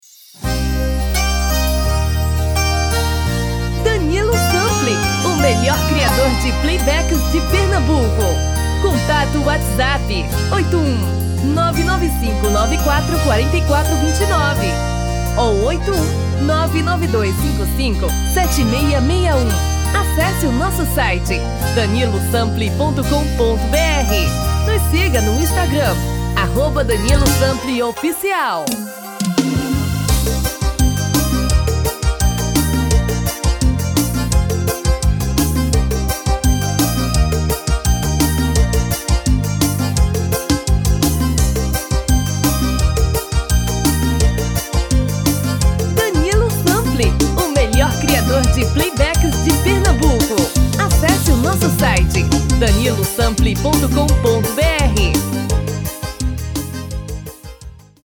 DEMO 1: tom original / DEMO 2: um tom e meio abaixo